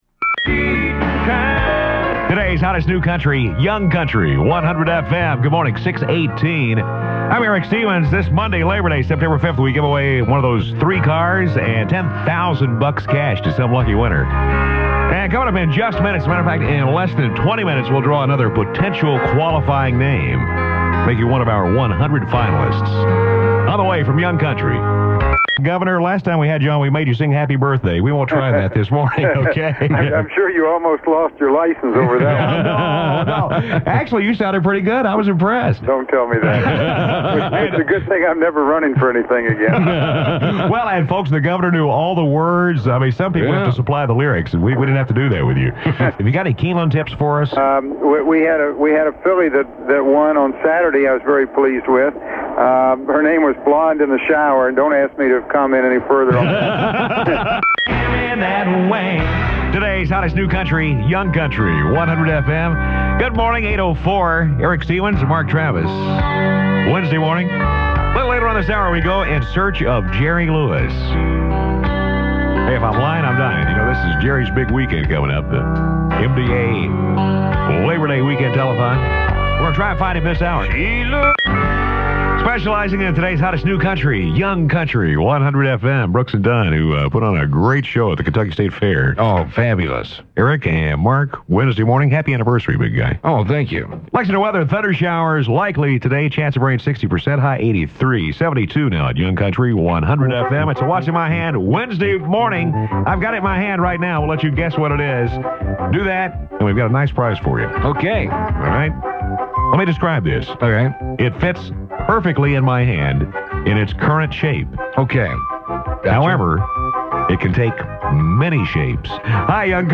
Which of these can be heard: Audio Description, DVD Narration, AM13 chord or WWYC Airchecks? WWYC Airchecks